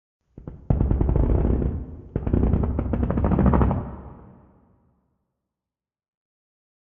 Minecraft Version Minecraft Version latest Latest Release | Latest Snapshot latest / assets / minecraft / sounds / ambient / nether / warped_forest / creak2.ogg Compare With Compare With Latest Release | Latest Snapshot
creak2.ogg